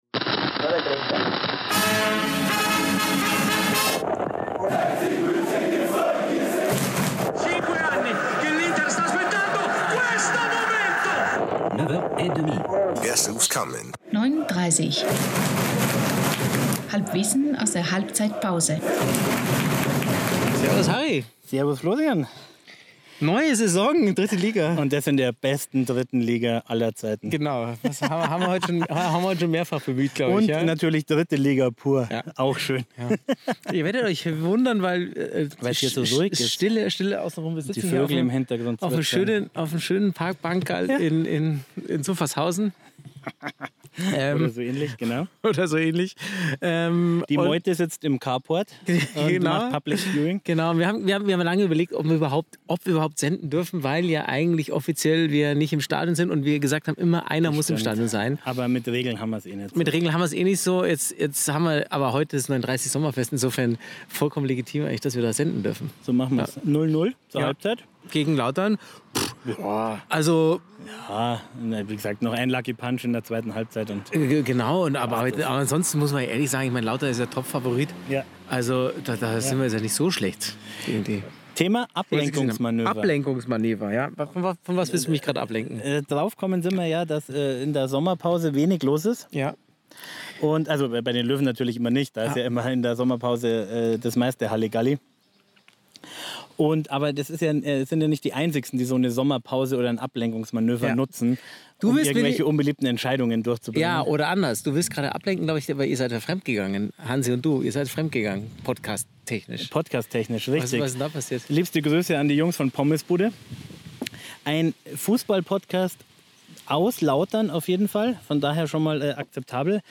die Vögel zwitschern im Hintergrund und die beiden plaudern über ...
Der Podcast aus der Westkurve im Grünwalderstadion bei den Spielen des TSV 1860.